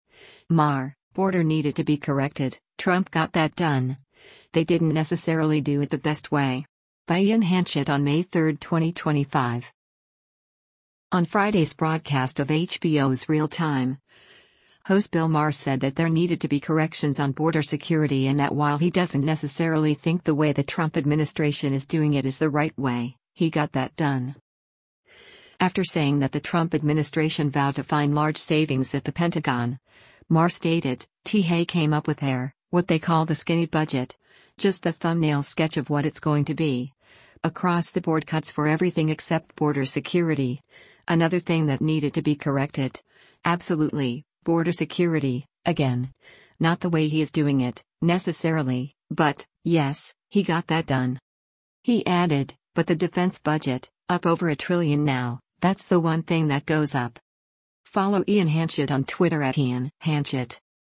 On Friday’s broadcast of HBO’s “Real Time,” host Bill Maher said that there needed to be corrections on border security and that while he doesn’t necessarily think the way the Trump administration is doing it is the right way, “he got that done.”